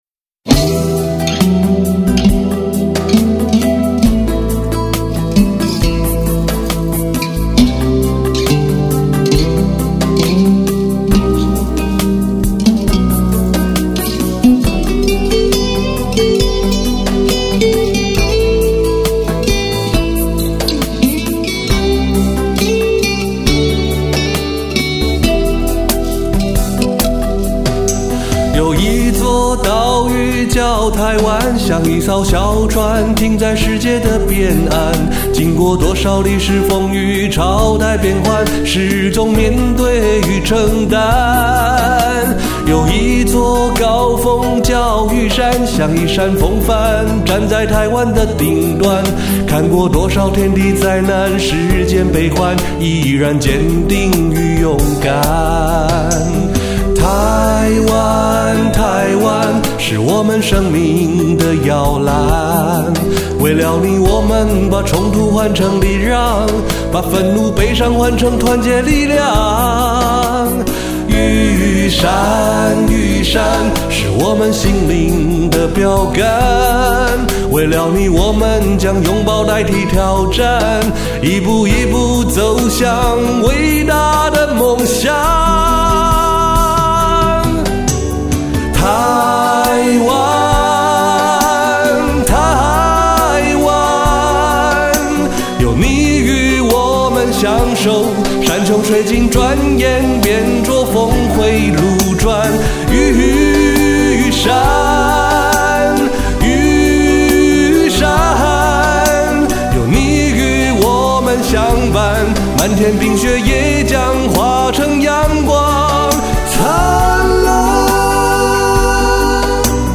吉他